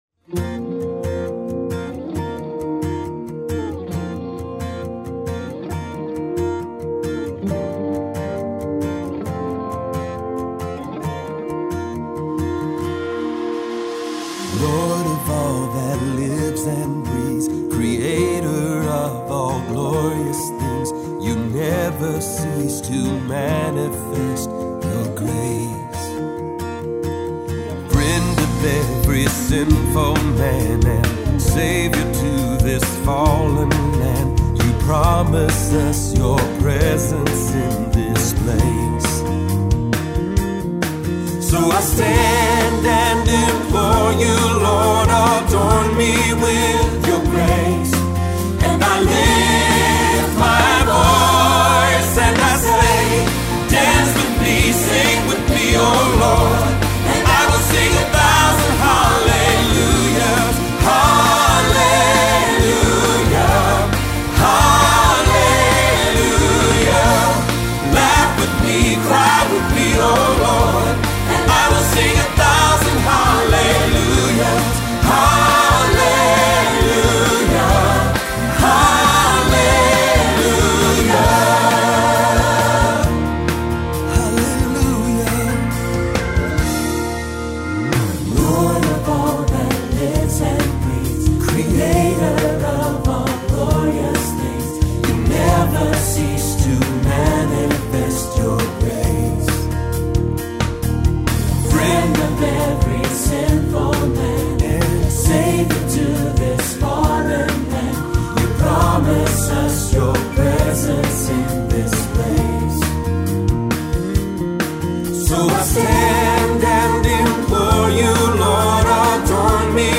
2026 Choral Selections